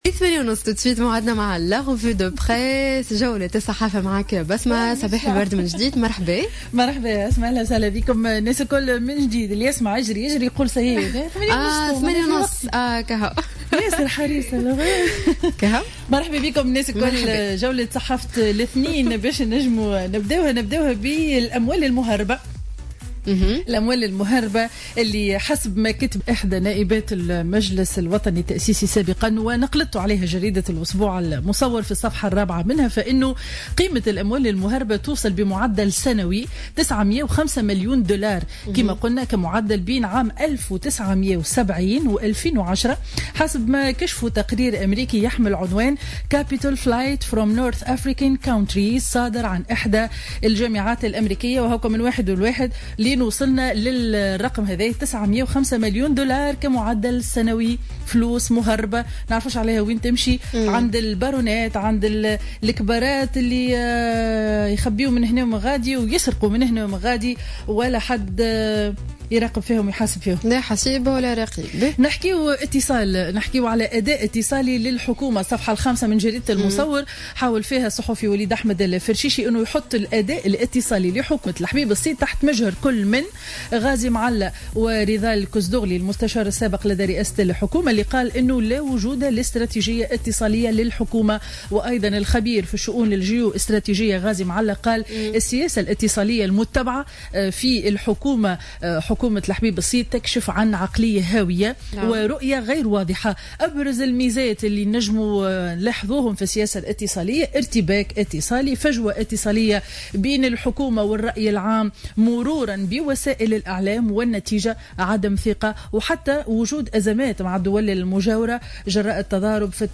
Revue de presse du lundi 17 août 2015